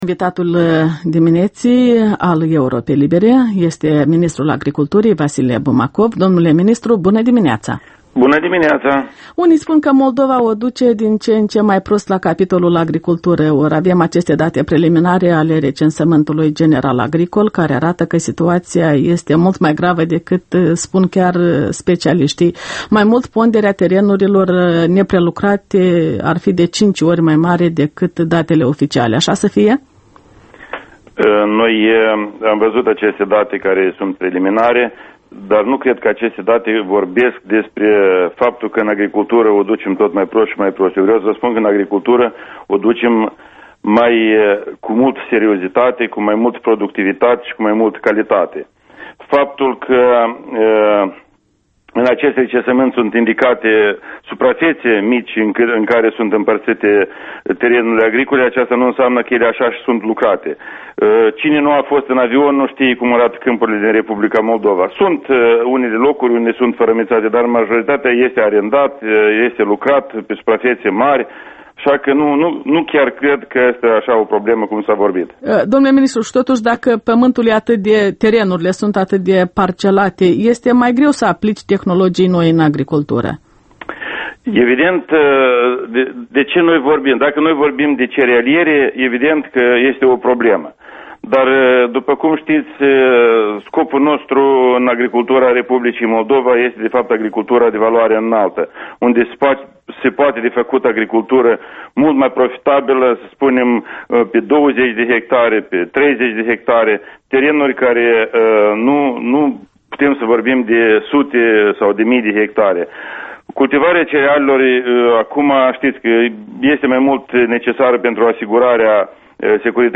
Interviul matinal la Europa Liberă: cu ministrul agriculturii Vasile Bumacov